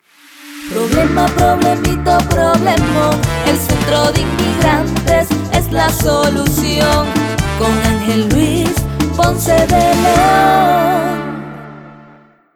Music Genre: Merengue Pop (Female Singer)